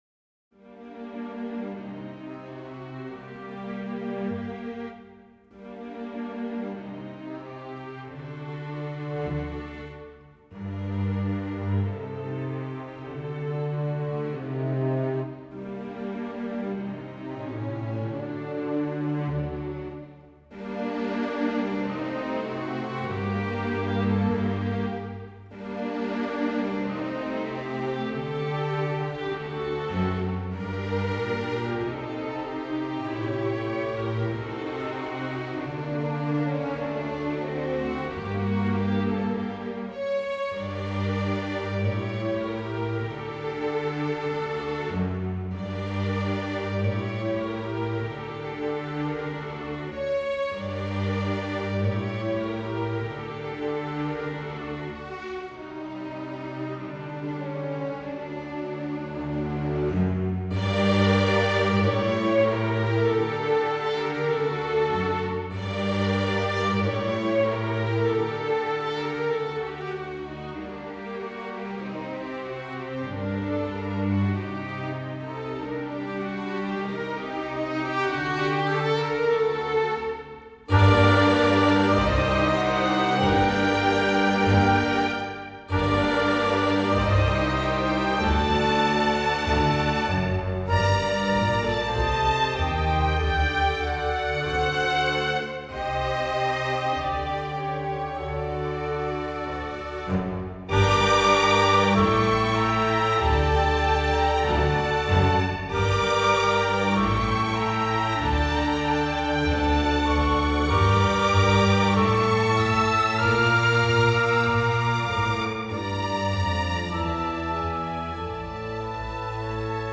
van andere composities is er alleen een synthetische "weergave" (gemaakt met StaffPad).
Orkest